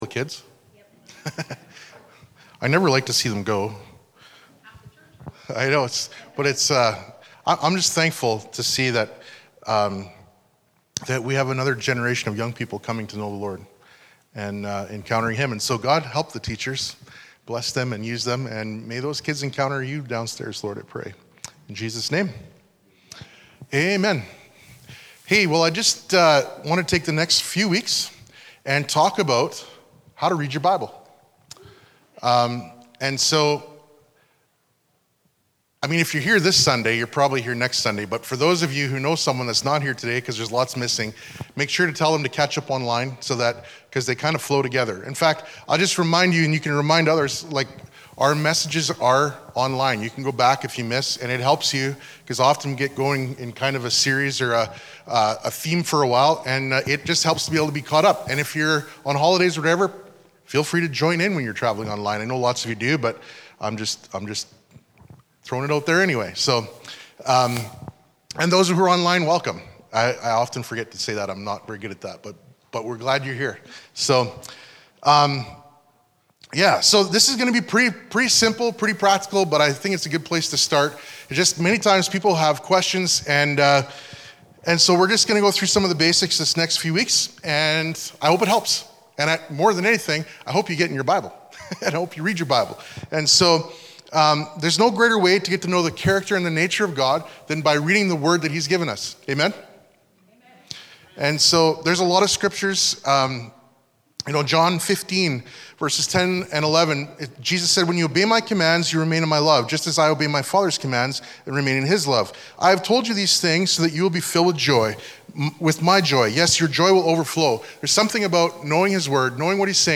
Sermons | Family Church in Maple Creek